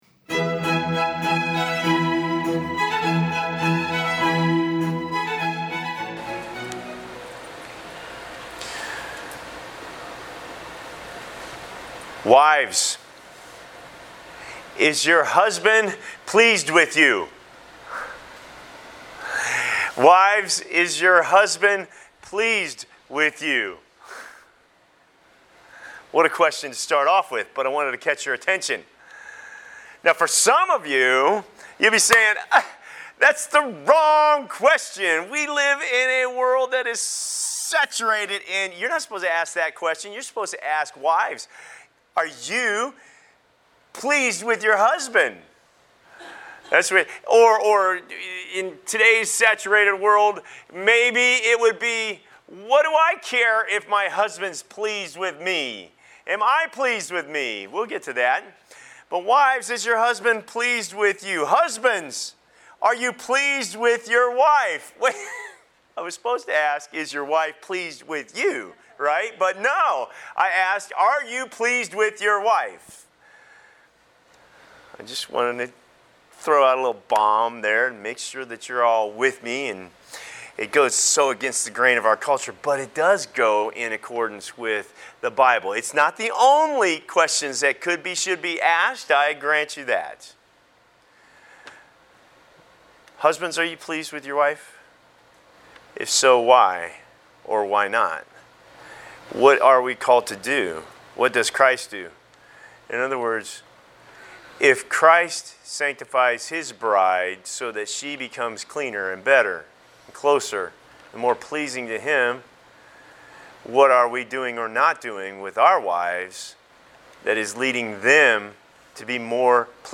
July 14, 2019 A Faith That Pleases Passage: Hebrews 11.5-6 Service Type: Morning Worship Service Bible Text: Hebrews 11.5-6 | Does your faith please God?